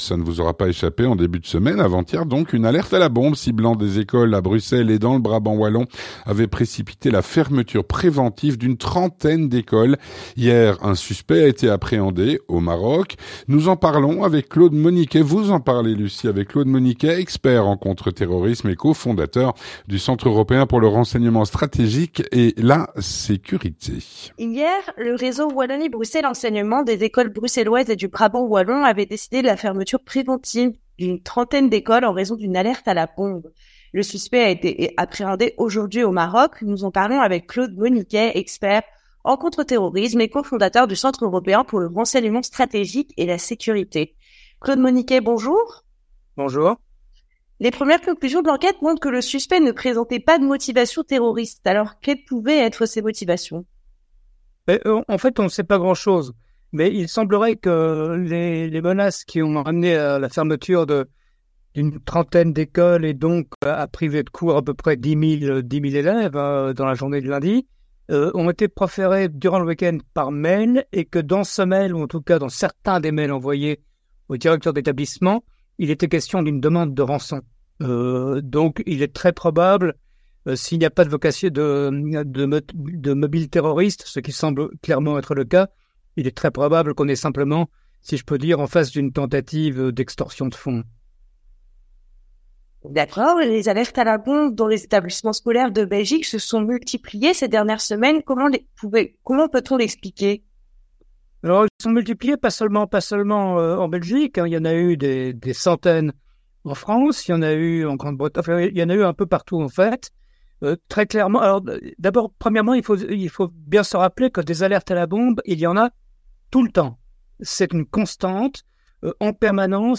Présenté par